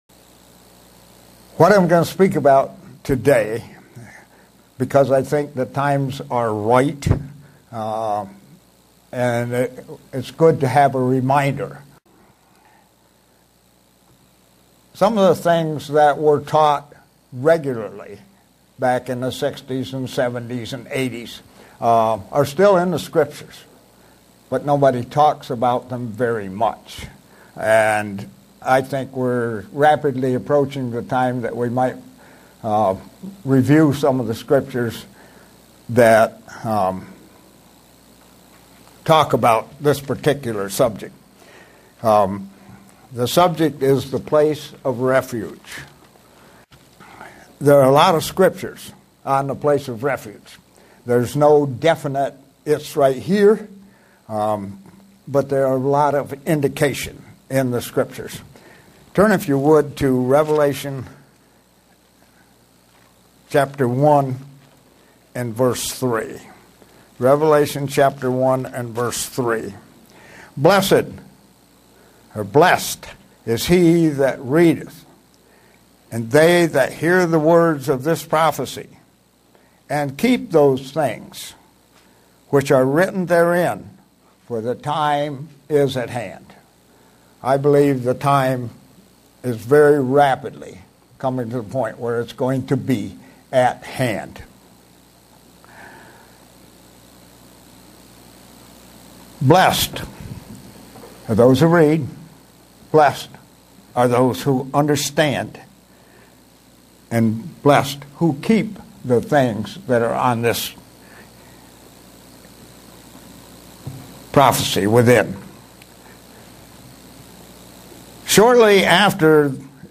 Given in Buffalo, NY
Print The many ways the place of refuge is depicted in the Bible. sermon Studying the bible?